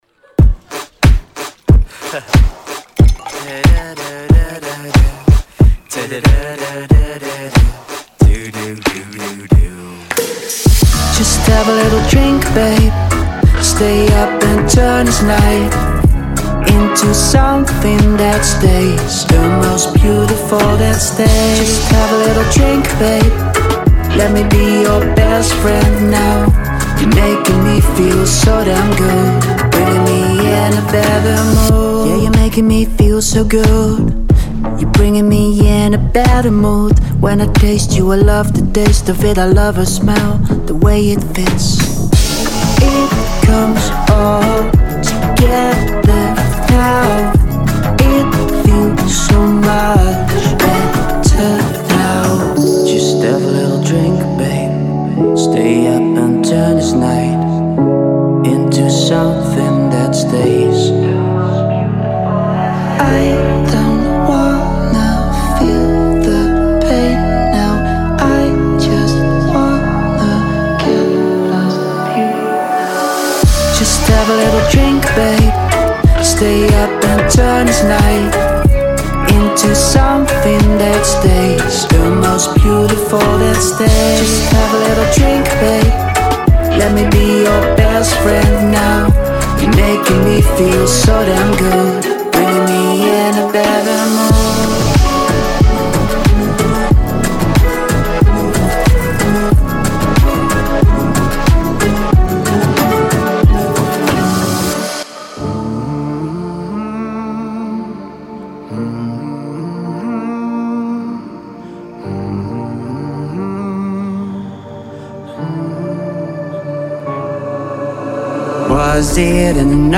2025-12-30 Electro Pop · Trap 142 推广
适用于独立流行、电子流行、未来流行、R&B、Trap 等多种音乐风格。
其中包含 4 个完整的清唱人声采样和 4 个音色库，每个音色库都包含丰富的细节音色和旋律。
这些音色库被细分为不同的类别，包括音乐循环、贝斯循环、人声短语、鼓循环等等。
此素材包包含 4 个清唱人声，分为 120 个主唱、和声及伴唱人声，